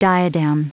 Prononciation du mot diadem en anglais (fichier audio)
Prononciation du mot : diadem